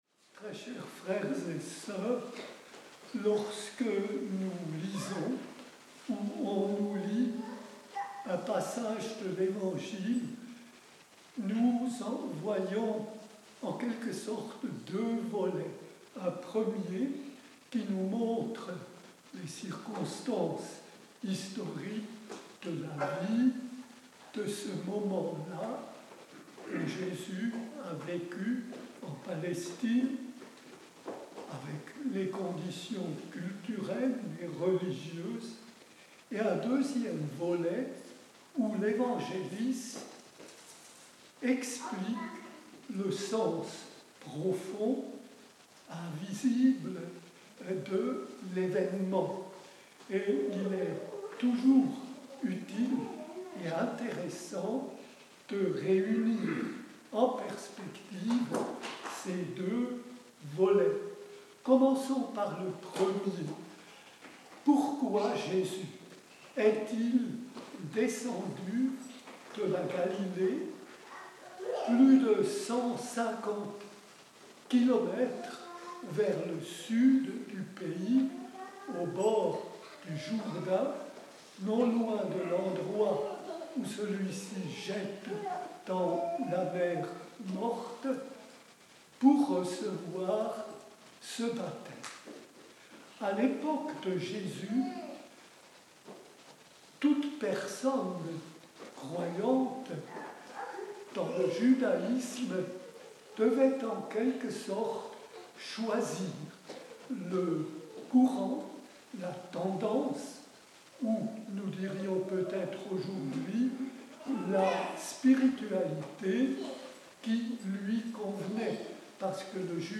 Pour découvrir son homllie, nous vous invitons à écouter un enregistrement en direct.
(Nous nous excusons de la qualité imparfaite de cet enregistrement, qui est néanmoins audible.)